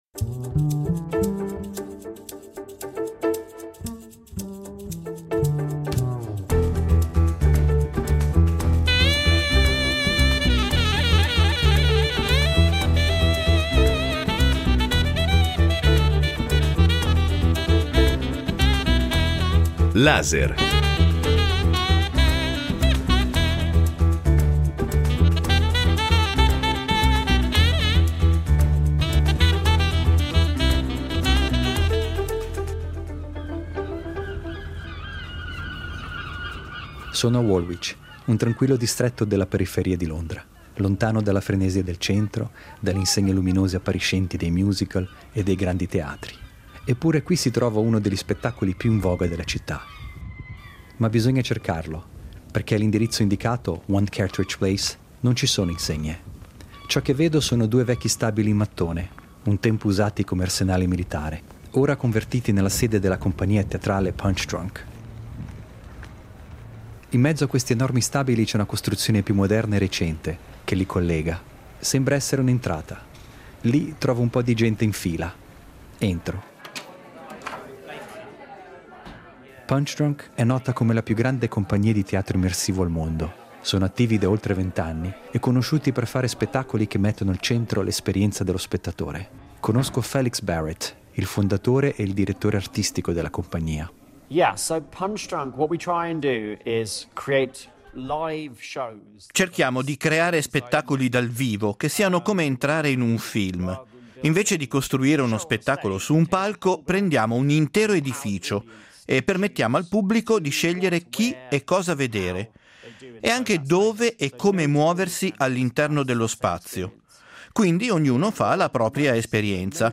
documentario radiofonico